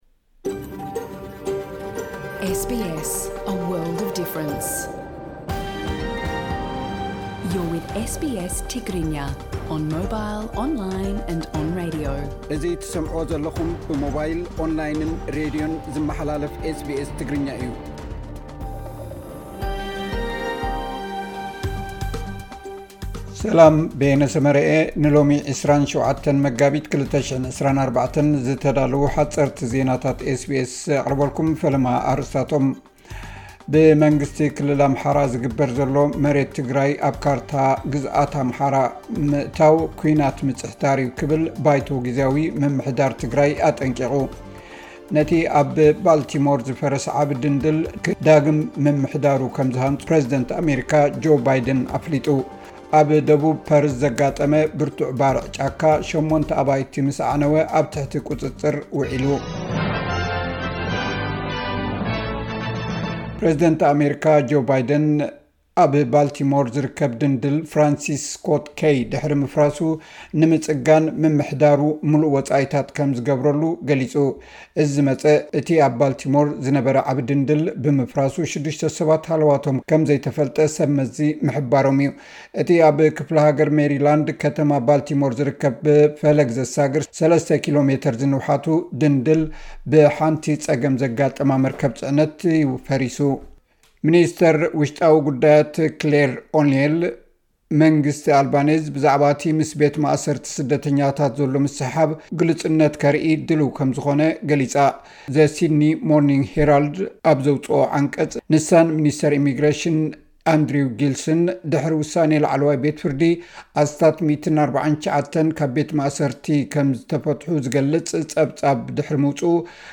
ሓጸርቲ ዜናታት ኤስ ቢ ኤስ ትግርኛ (27 መጋቢት 2024)